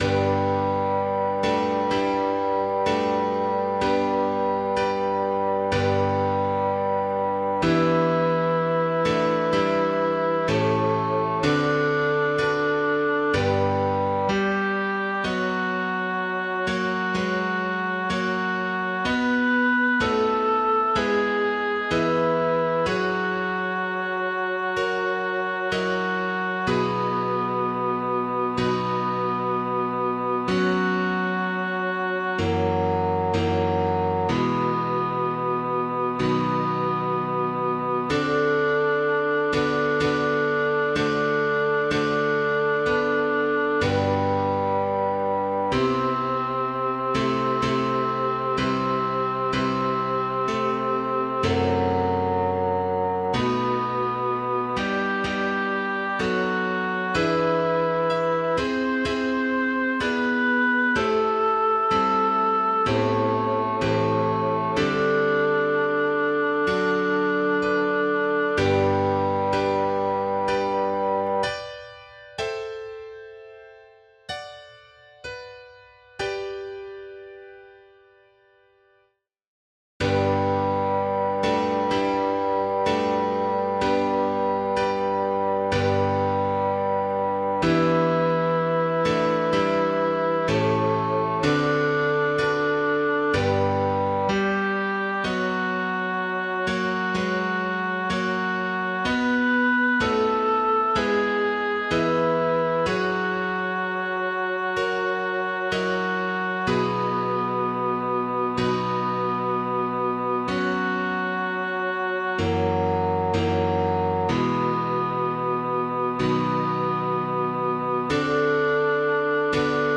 Bas 2
ave maris stella-b2.mp3